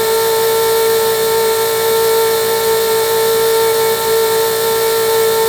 Sfx_tool_spypenguin_rotate_01.ogg